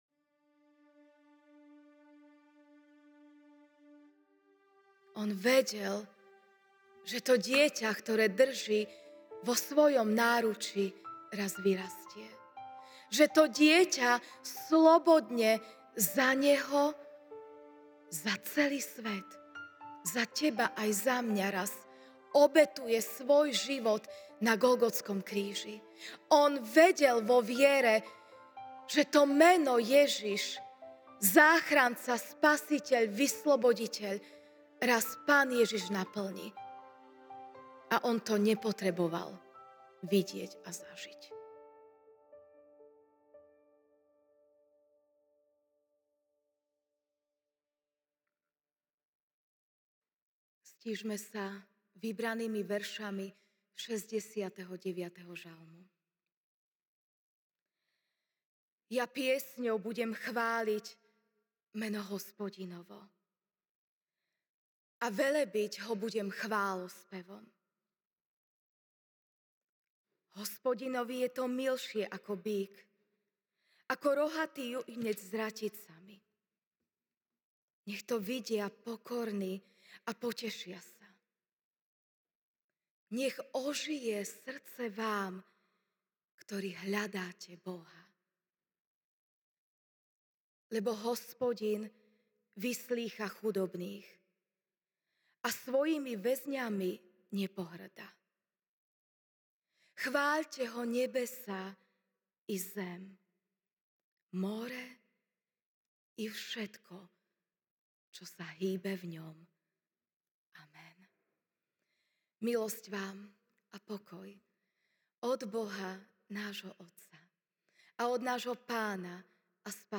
Ranná kázeň